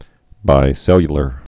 (bī-sĕlyə-lər)